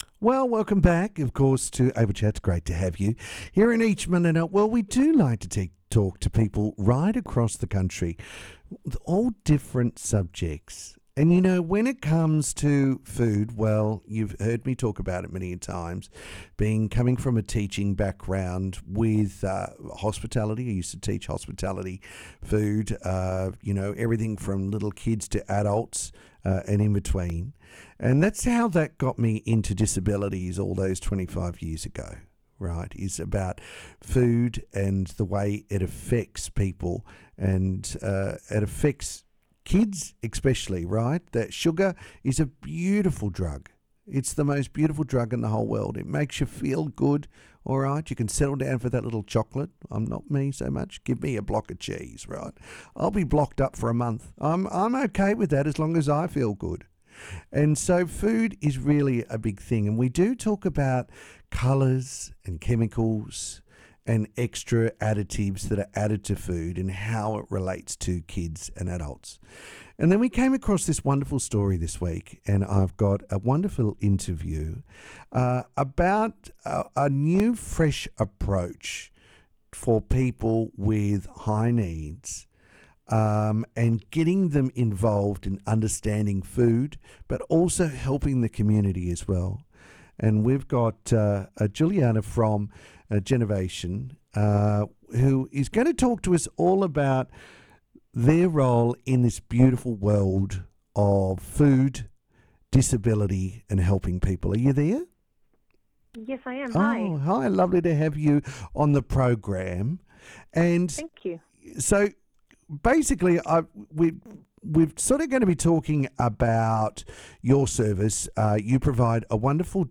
Our friends at a local disability service all work together to make wonderful fruit and vegetables be available to be delivered from the farm to your home directly. What a wonderful conversation we had with our friends check it out.